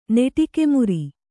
♪ neṭike muri